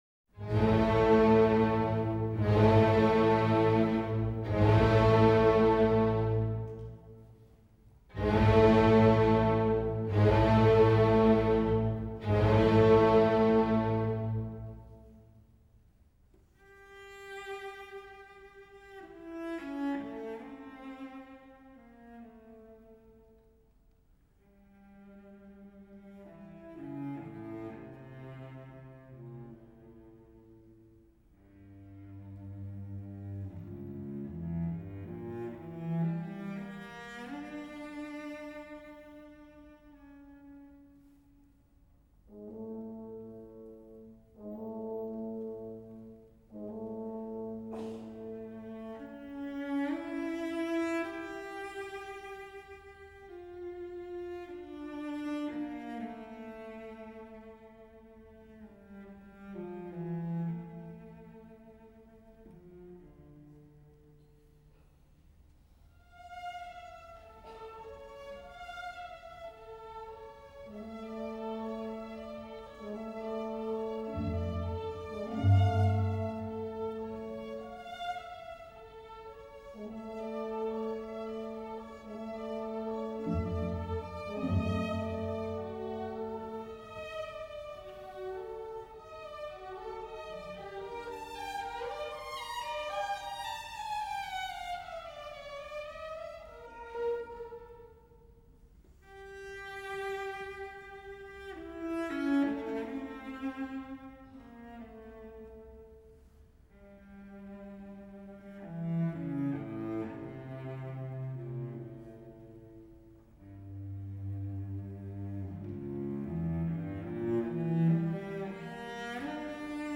82 года со дня рождения болгарского певца (бас) Николая Гяурова